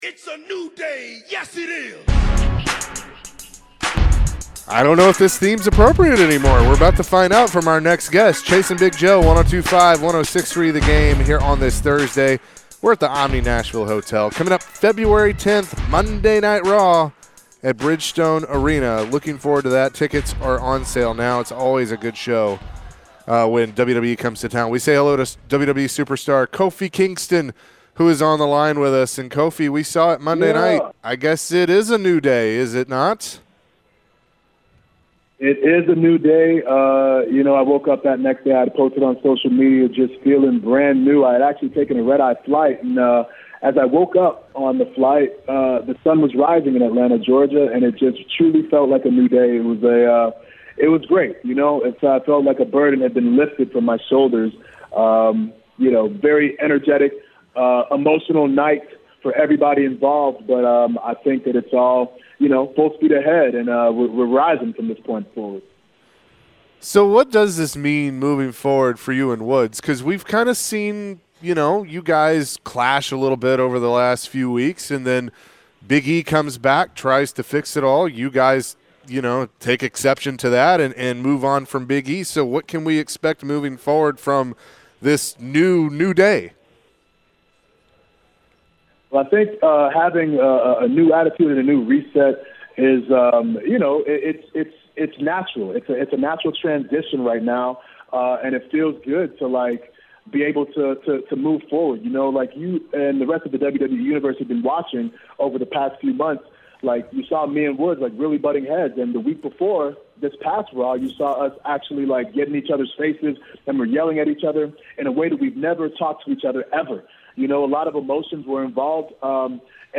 WWE Superstar Kofi Kingston joined the show to discuss the latest in the WWE. Monday Night Raw comes to Nashville on February 10th.